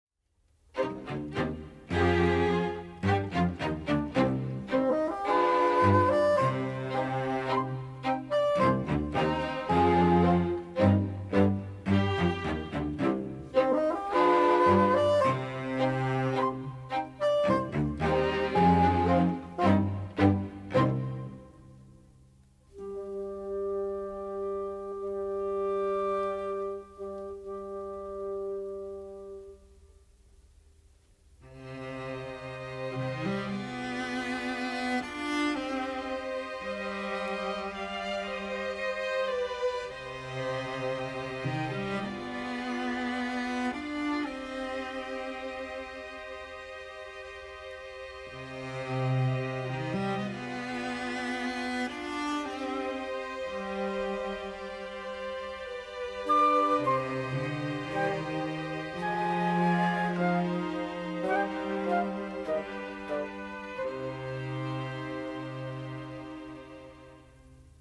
for a small orchestra